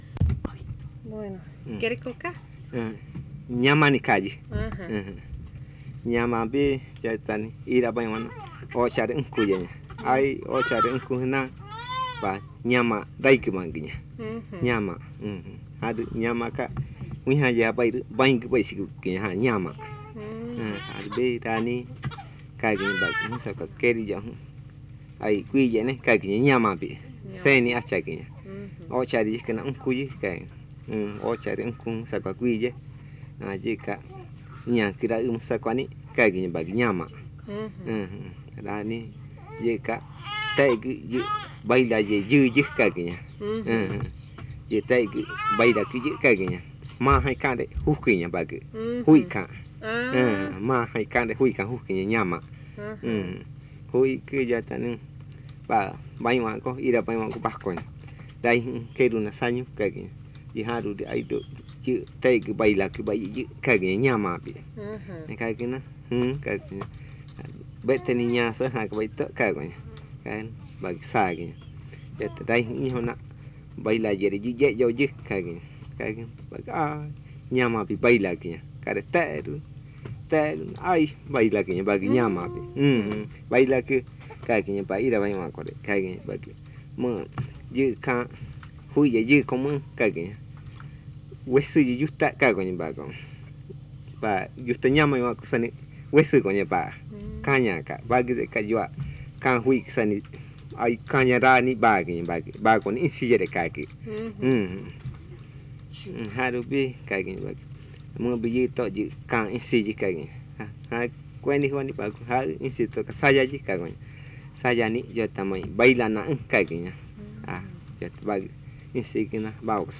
Buenavista, río Putumayo (Colombia)
Dos narraciones. La primera trata sobre el ciervo que vino a bailar con la gente y la segunda (en el minuto 3:35) trata sobre un huati que se transforma en ma gone jojo, una rana.